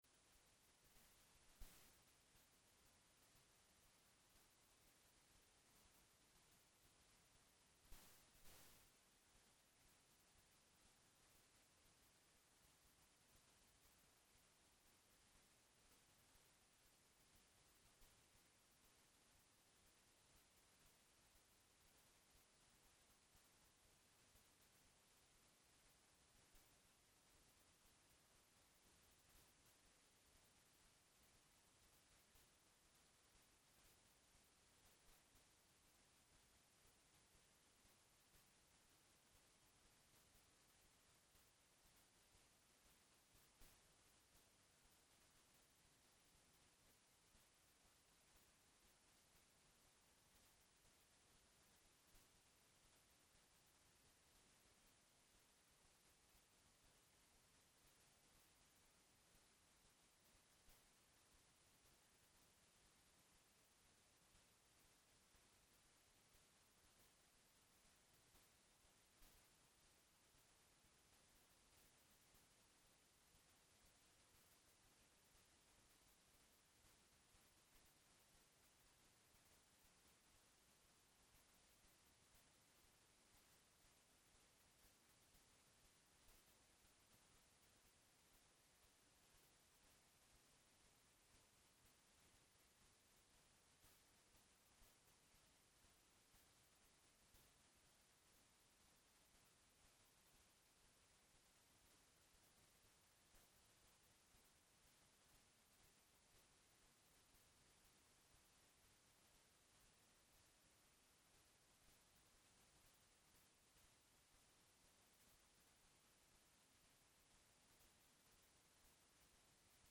Event type Lecture